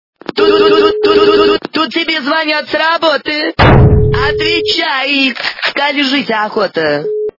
» Звуки » Смешные » Говорящий телефон - Тут тебе звонят с работы, отвечай...
При прослушивании Говорящий телефон - Тут тебе звонят с работы, отвечай... качество понижено и присутствуют гудки.